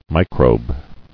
[mi·crobe]